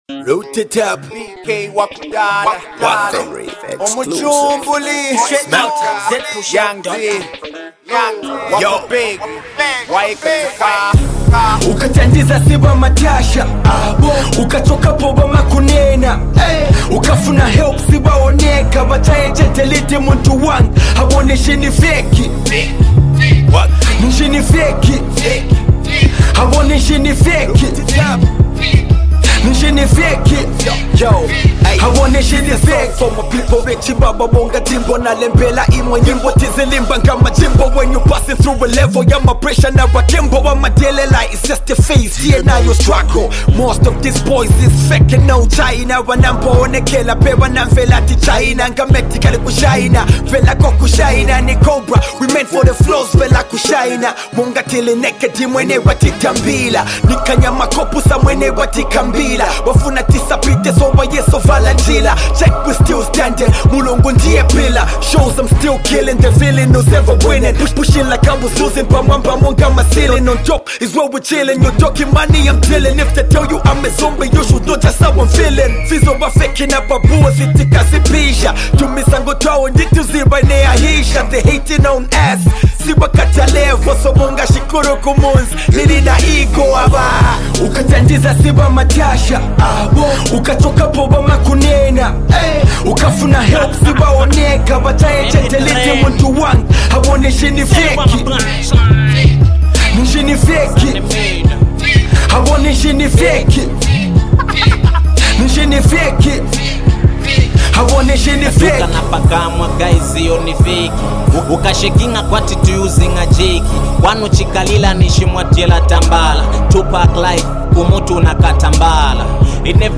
well-balanced jam